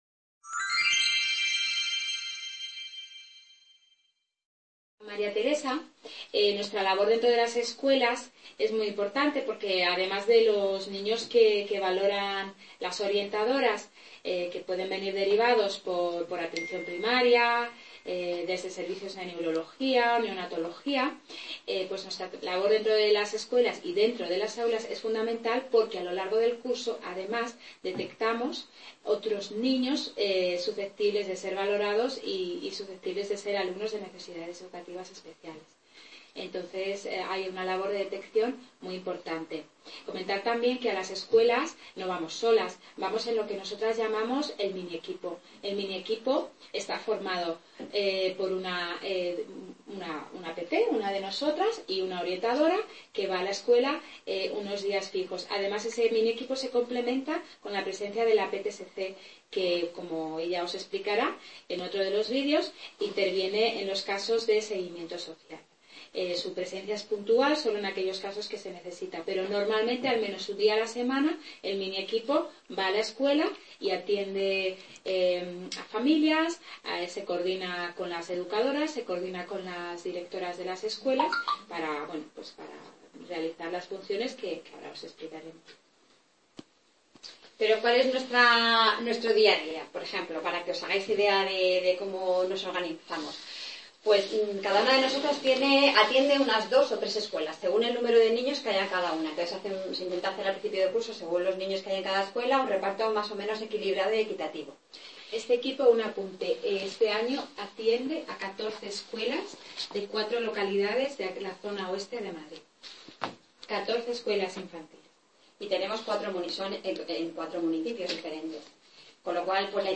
Maestras especialistas en Pedagogía Terapéutica nos muestran su trabajo.
Video Clase